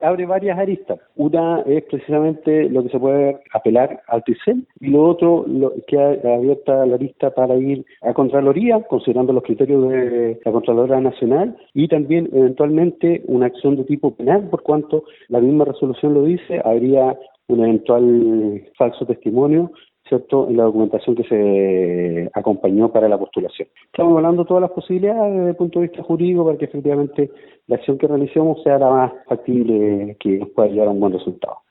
cuna-calbuco-exalcalde.mp3